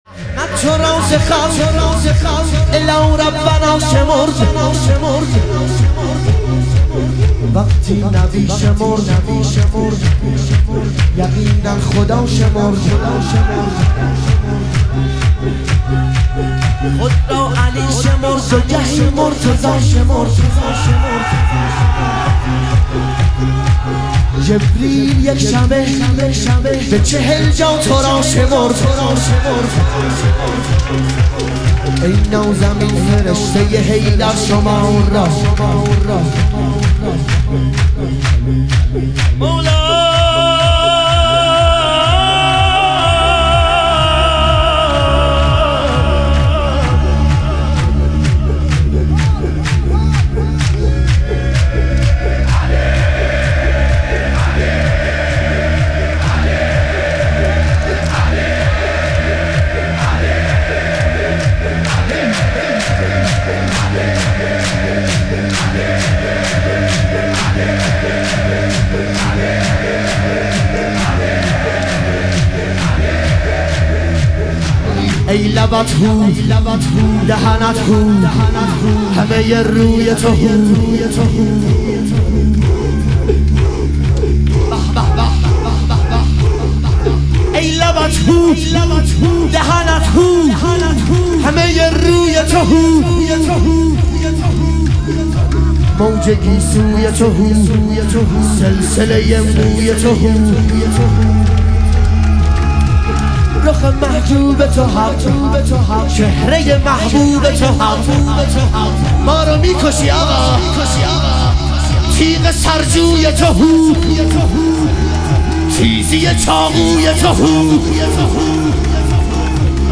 شهادت امام صادق علیه السلام - شور